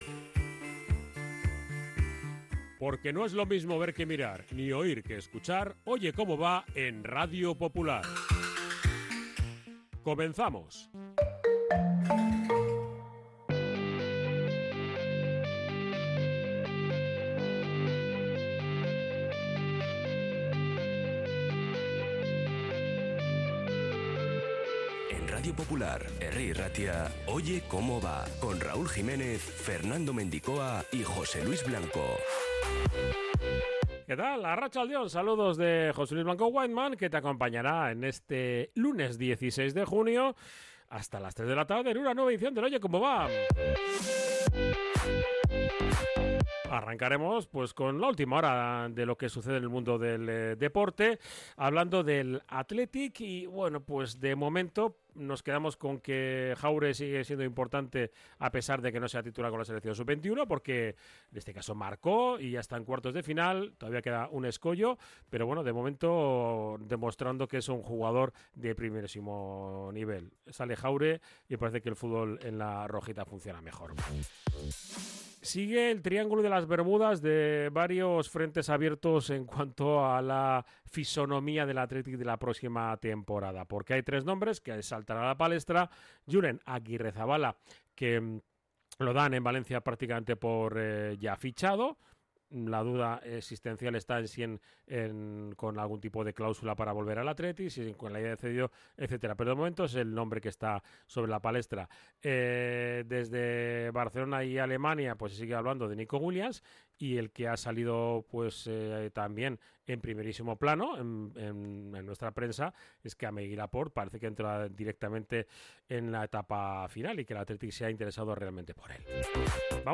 Actualidad Athletic y entrevista